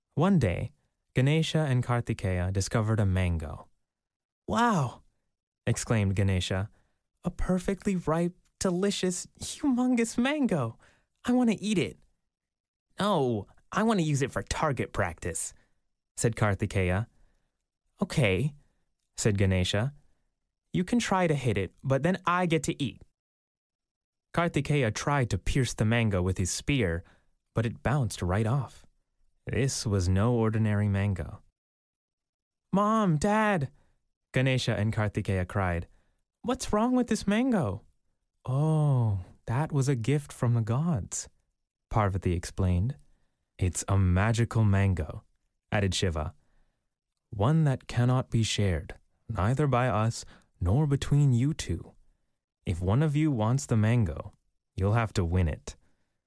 Whether it's animation, video games, commercials, or audiobooks, I've got your back in bringing your creative endeavors to life, recorded remotely from my home studio.
Audiobook - Ganesha's Great Race